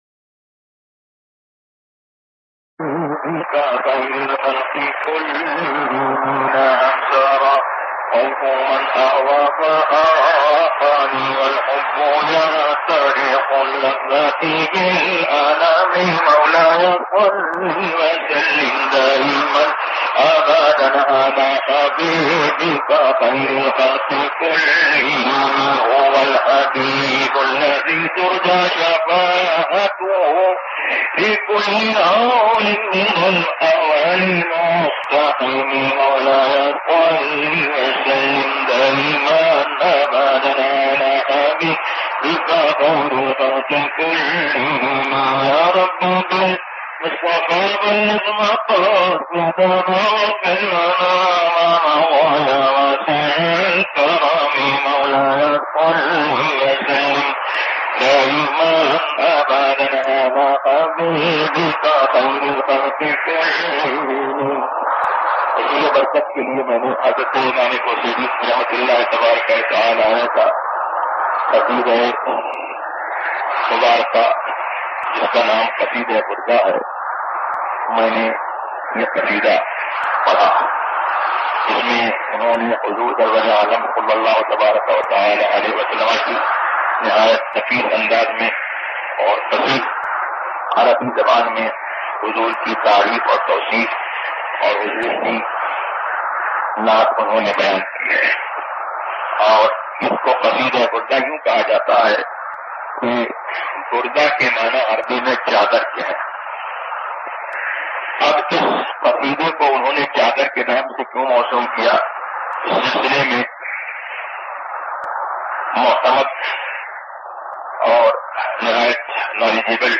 موضوع تقاریر آواز تاج الشریعہ مفتی اختر رضا خان ازہری زبان اُردو کل نتائج 958 قسم آڈیو ڈاؤن لوڈ MP 3 ڈاؤن لوڈ MP 4 متعلقہ تجویزوآراء